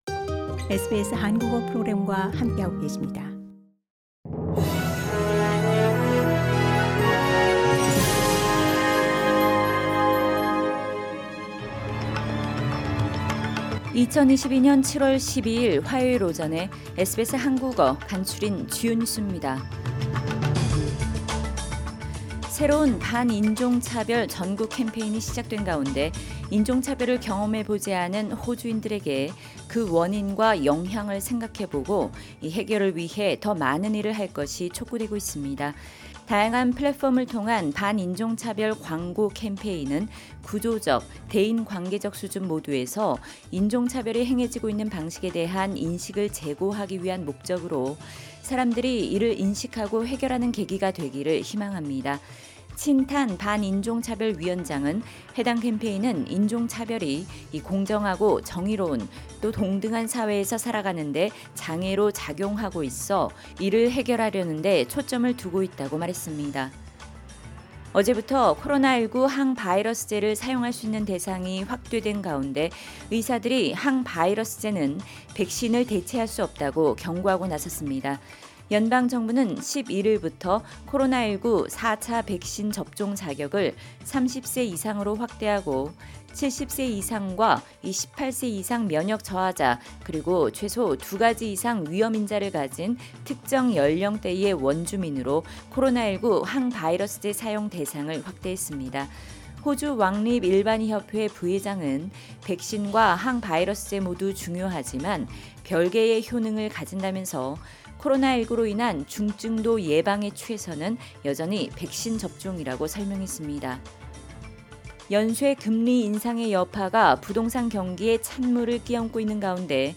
SBS 한국어 아침 뉴스: 2022년 7월 12일 화요일